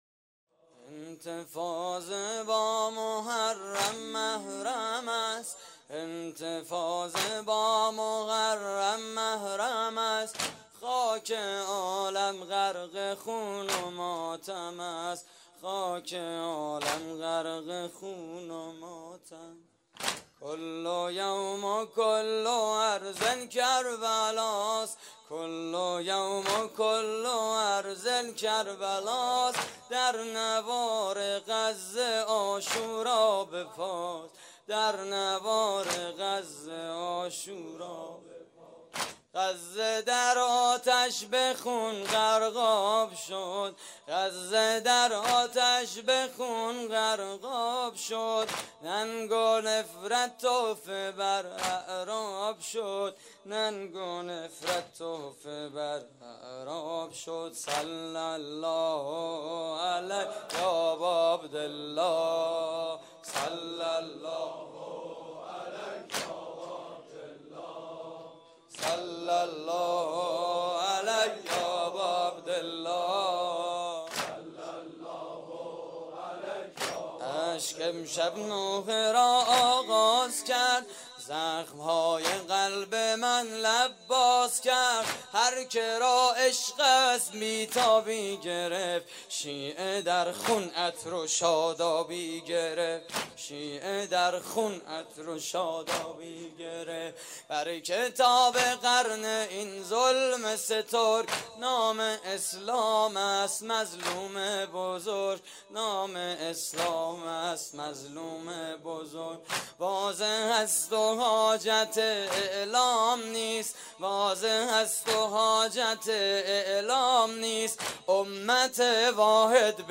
واحد: انتفاضه با مُحرم مَحرم است
مراسم عزاداری شب تاسوعای حسینی (محرم 1433)